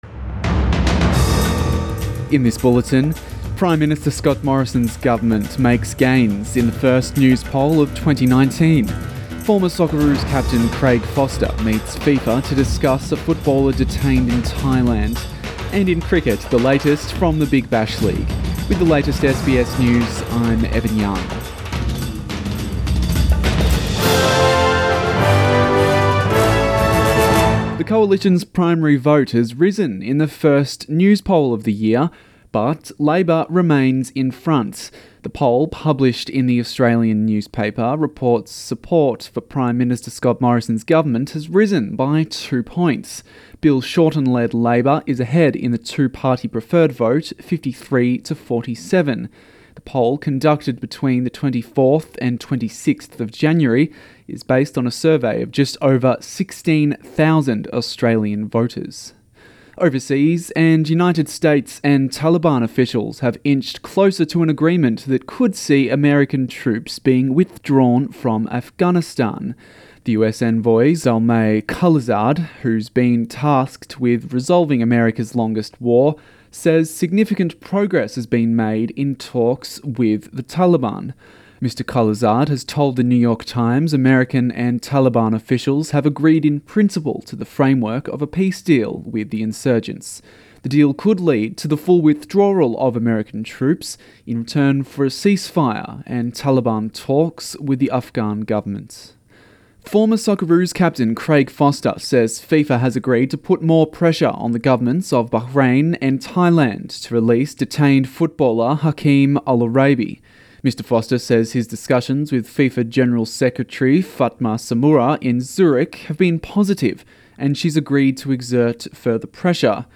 AM bulletin 29 January